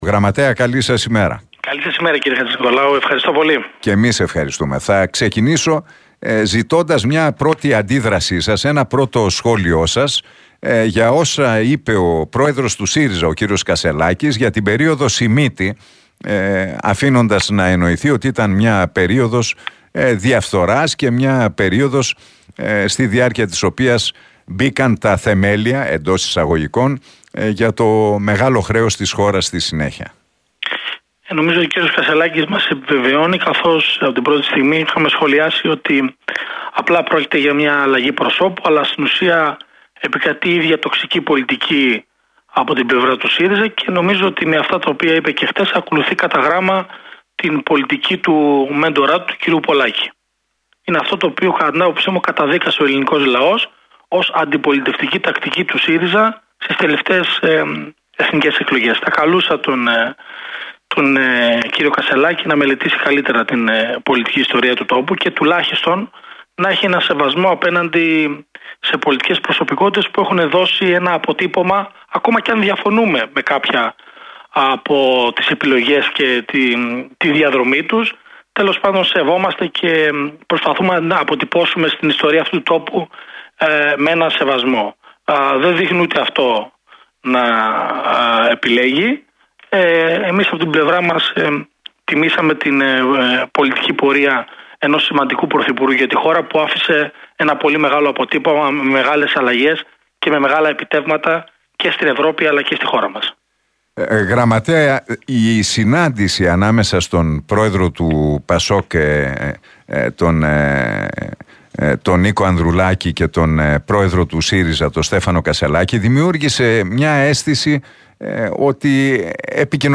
Ο Γραμματέας του ΠΑΣΟΚ - ΚΙΝΑΛ, Ανδρέας Σπυρόπουλος, μίλησε στην εκπομπή του Νίκου Χατζηνικολάου στον Realfm 97,8.